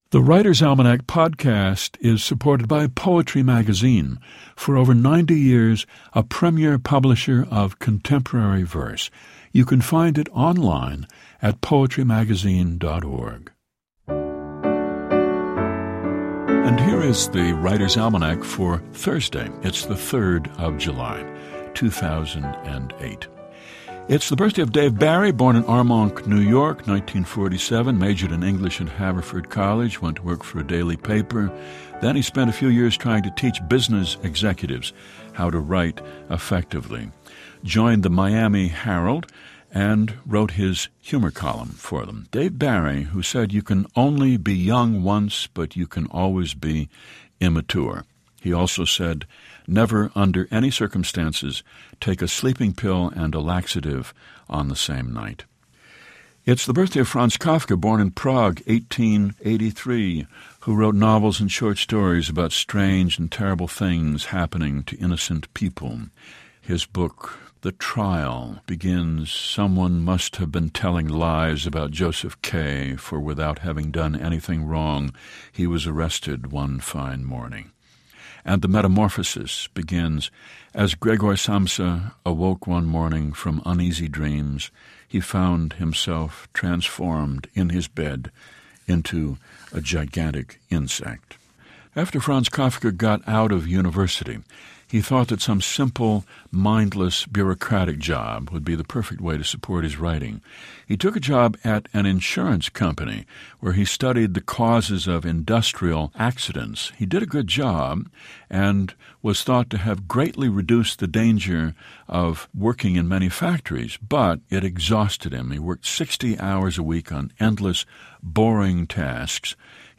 Each day, The Writer's Almanac features Garrison Keillor recounting the highlights of this day in history and reads a short poem or two.